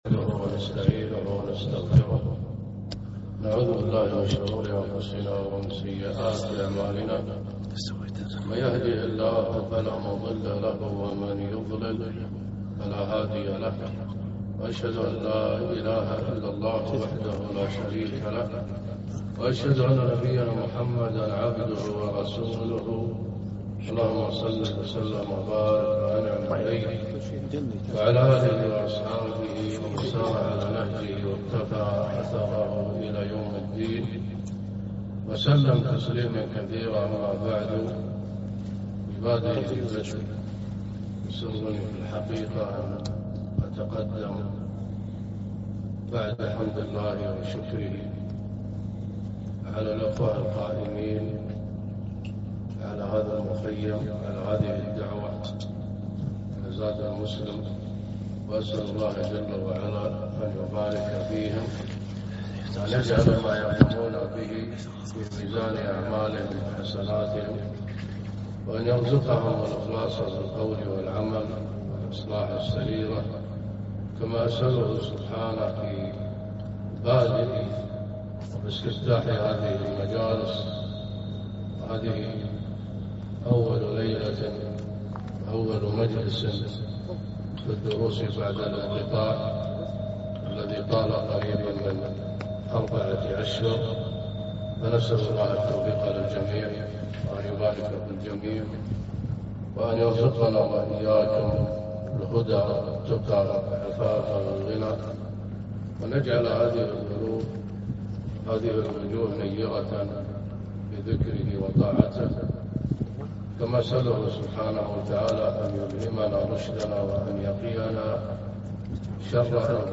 محاضرة - ضعف الإيمان أسبابه وعلاجه